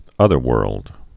(ŭthər-wûrld)